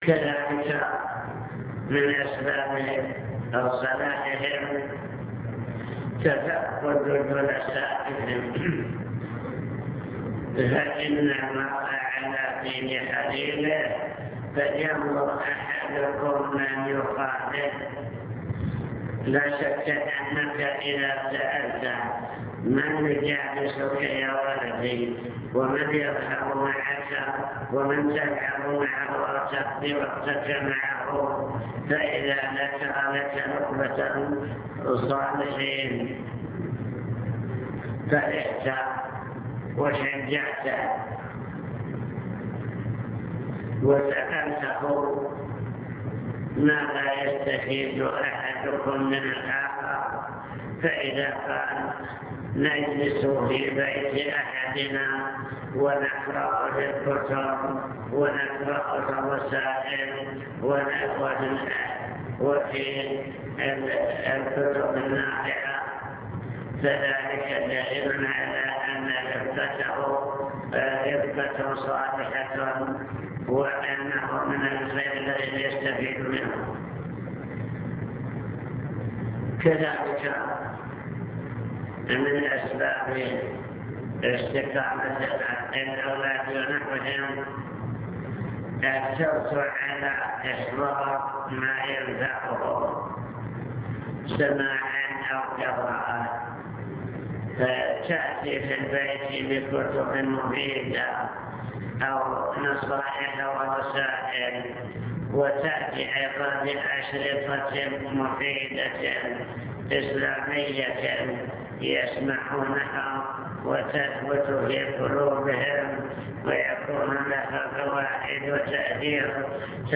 المكتبة الصوتية  تسجيلات - محاضرات ودروس  محاضرة بعنوان توجيهات للأسرة المسلمة